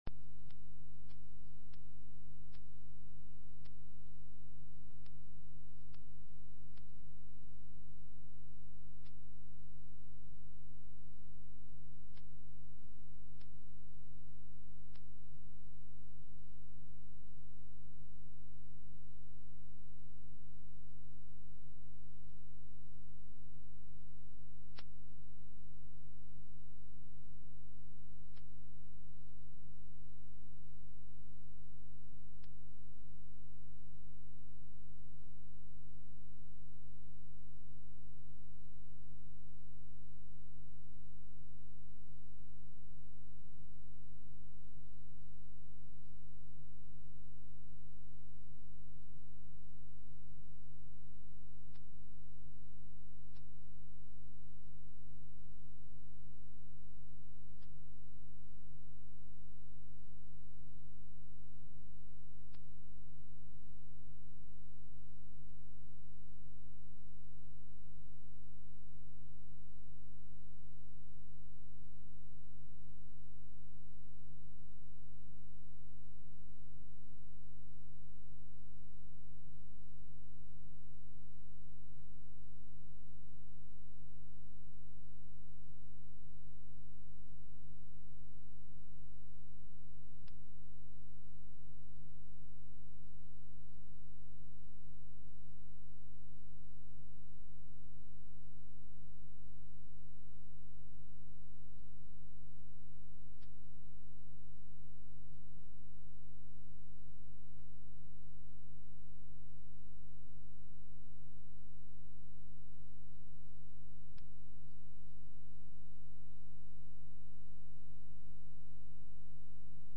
Seduta consiglio comunale del 27 gennaio 2017 - Comune di Sondrio